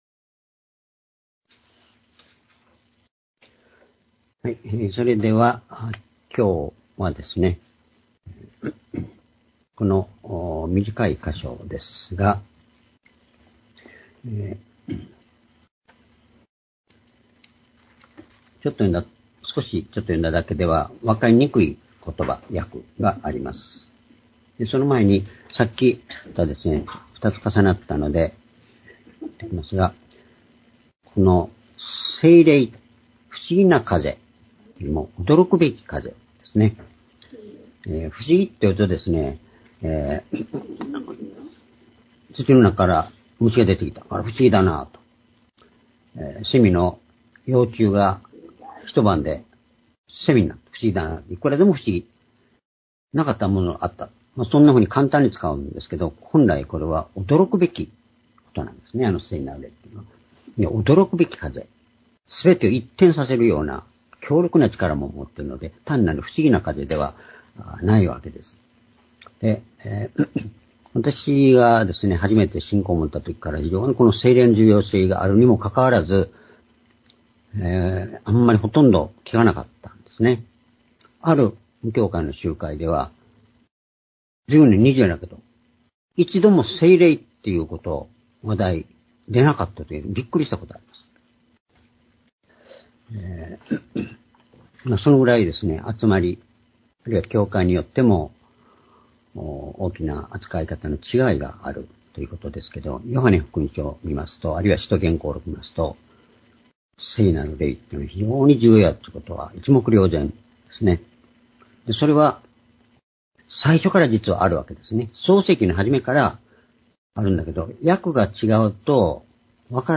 主日礼拝日時 ２０２３３年8月20日（主日礼拝 聖書講話箇所 「信じること、愛すること、そして復活」 ヨハネ11の28-37 ※視聴できない場合は をクリックしてください。